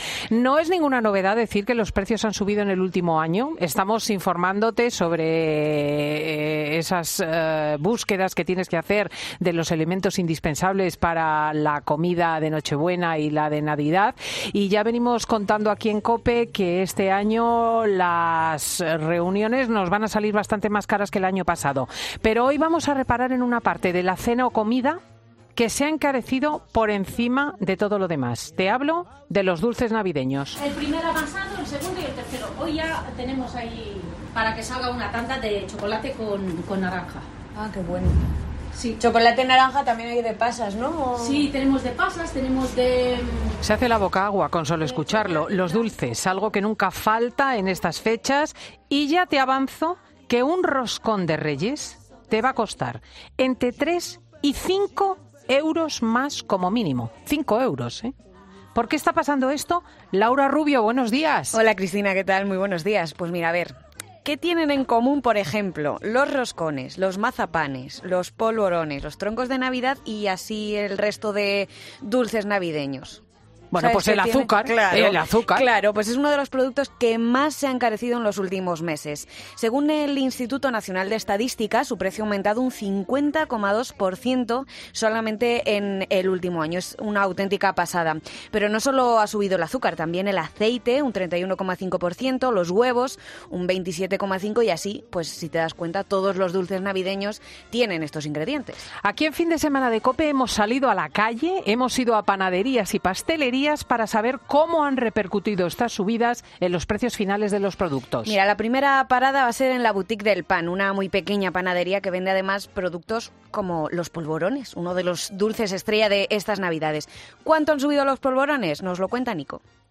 Fin de Semana de COPE recorre varias panaderías y pastelerías para saber cómo han repercutido las subidas de las materias primera en los precios finales de los dulces navideños